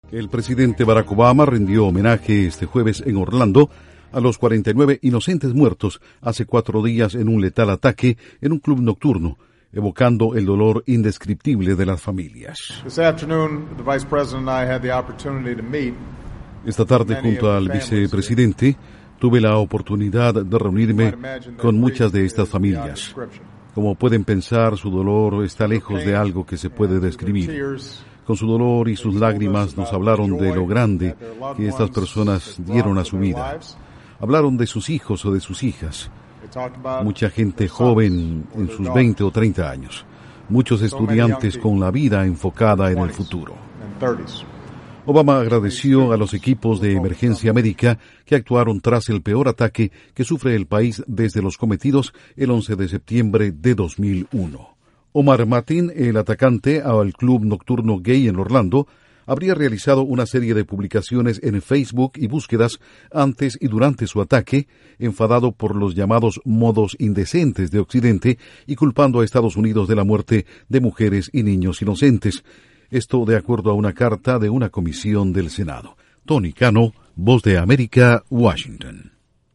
El presidente Barack Obama rinde homenaje a las 49 víctimas de la masacre en un club nocturno de Orlando. Informa desde la Voz de América